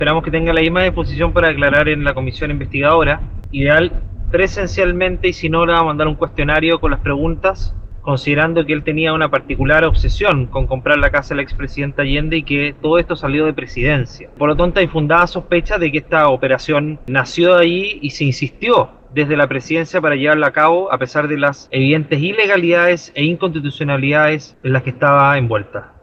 Una de ellas corresponde a solicitar al Presidente Boric que responda un cuestionario. Al respecto se refirió el diputado RN y presidente de la instancia, Andrés Longton.